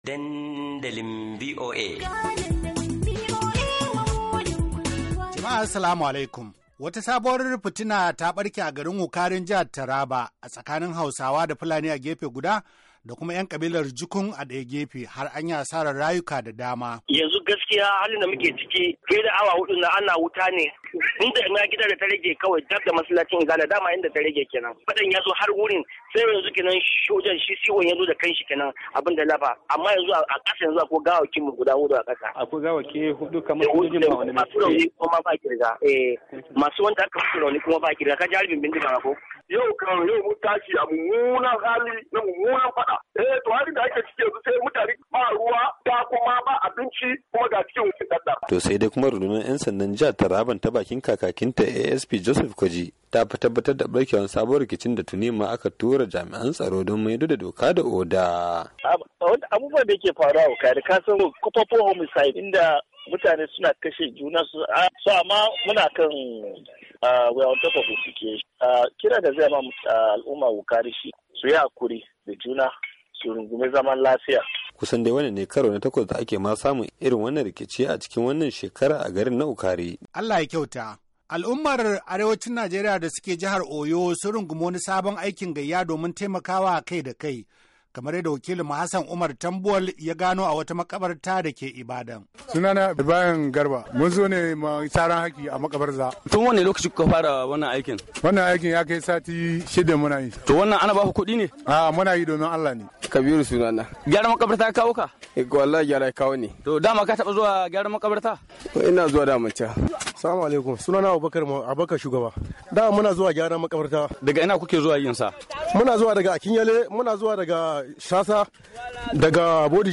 wadanda su na cikin magana da su ma aka fara jin karar...
LABARAI: Ana Gwabza Fada A Wukari, Hausawa A Oyo Kuma Su Na Ayyukan Taimakon Kai Da Kai - 2'02"